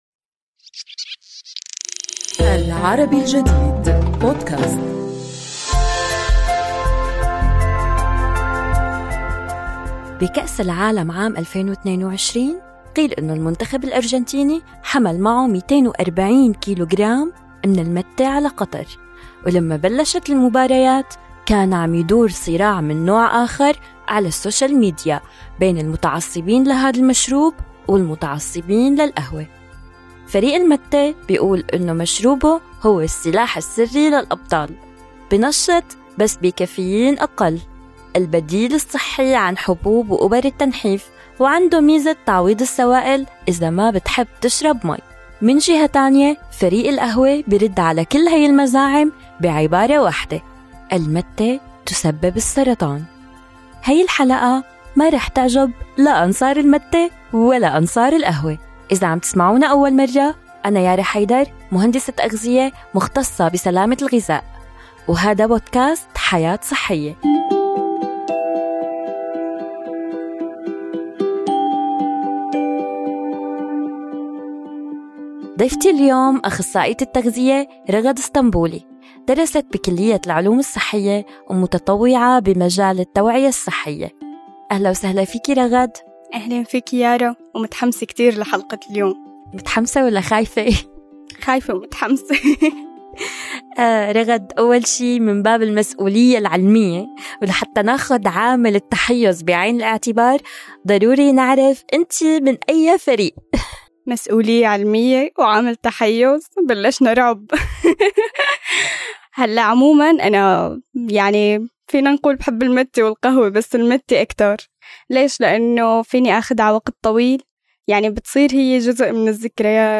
نستضيف أخصائية التغذية